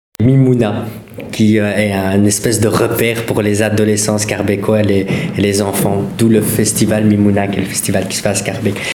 uitspraak